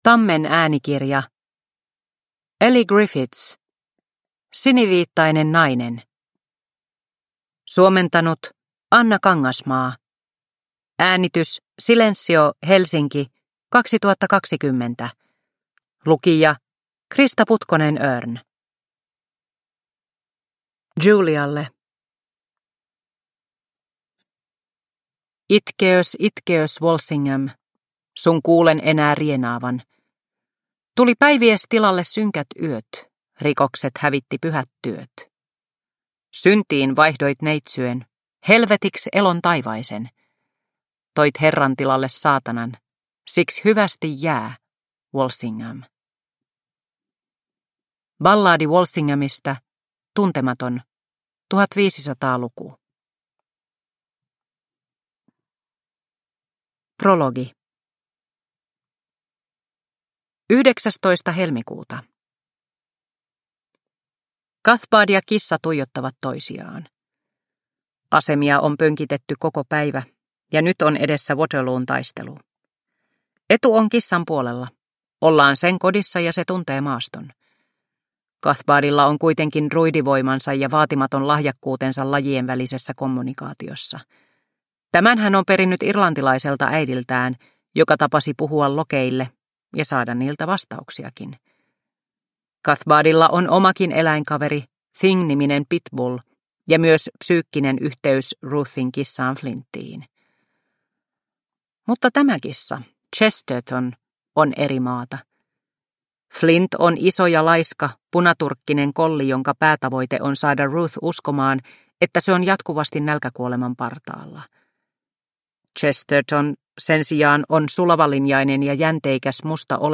Siniviittainen nainen – Ljudbok – Laddas ner